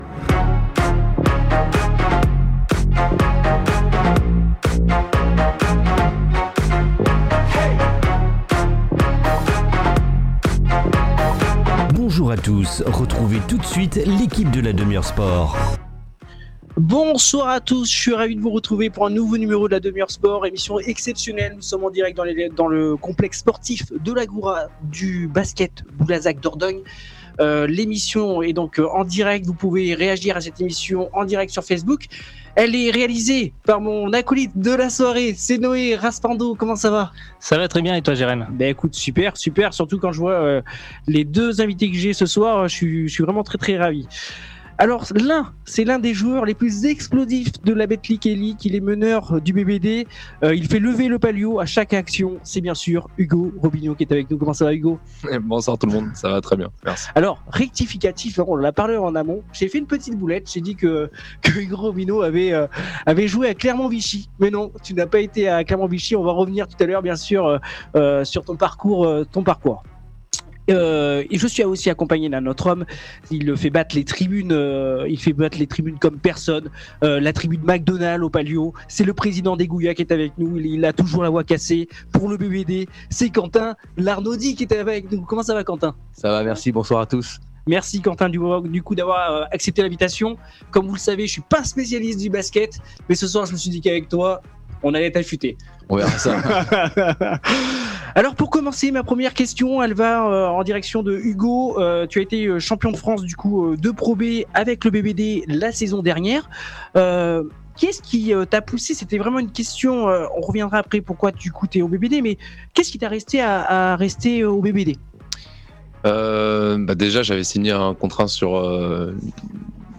Une émission exceptionnelle enregistrée en direct de l’Agora… et qui va plaire à tous les fans du BBD !
Deux invités, deux univers… une même passion :